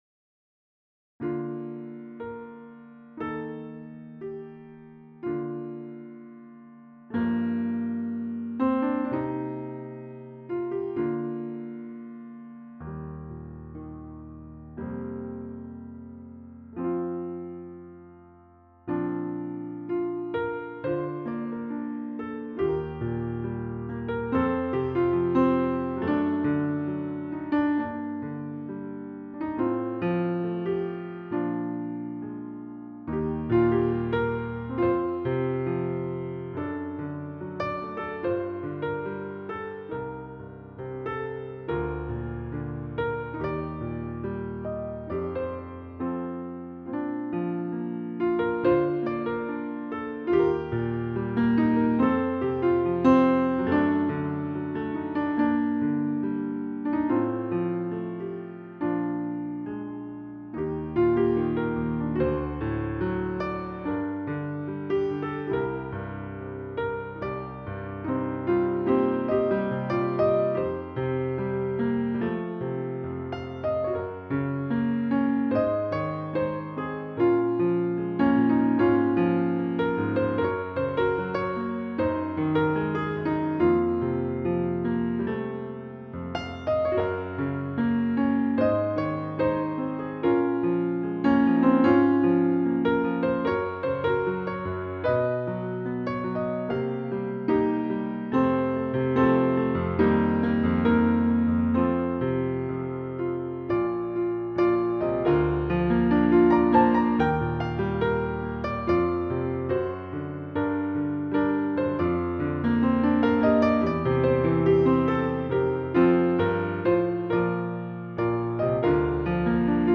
작업하다가 시간 때울겸 피아노 음원으로 원샷 녹음해봤습니다..^^;